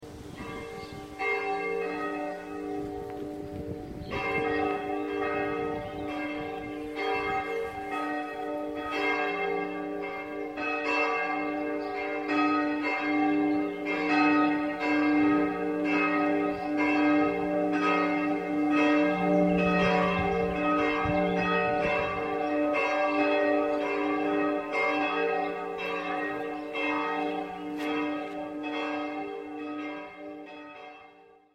So klingen die Glocken:
glockenlaeuten-maria-magdalenen-kirche.mp3